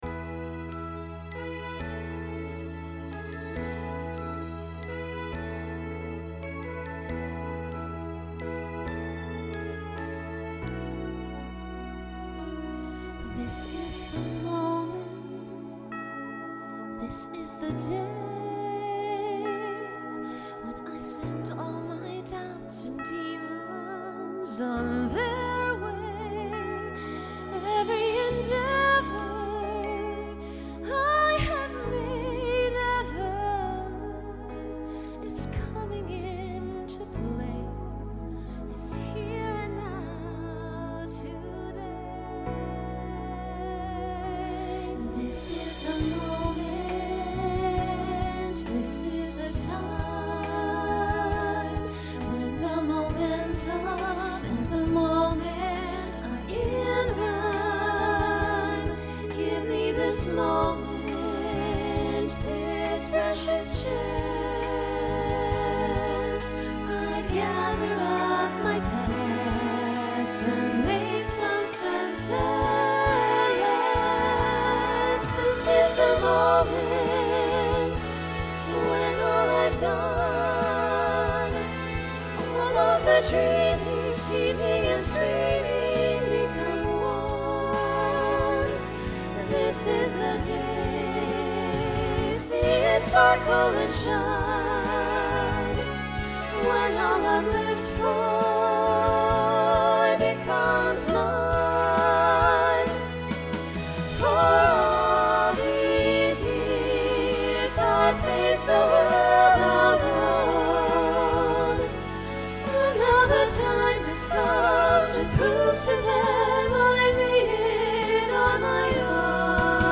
New SATB voicing for 2020.